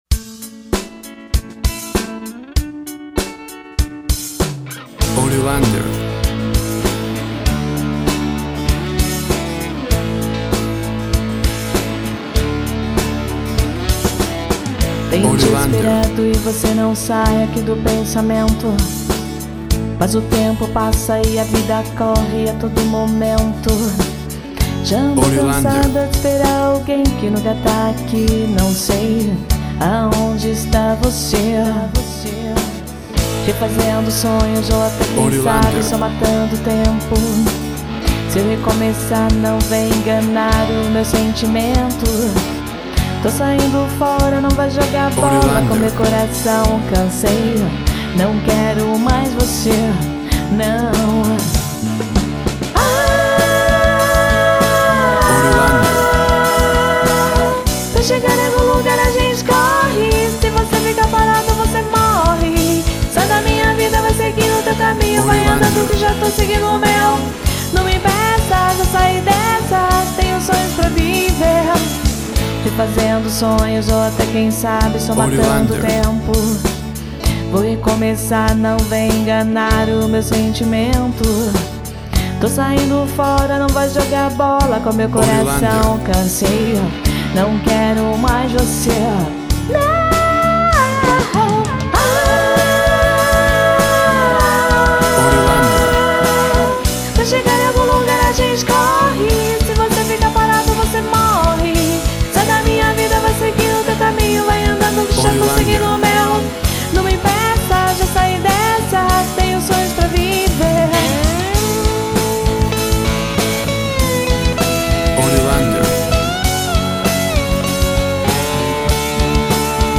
Pop rock.
Tempo (BPM) 98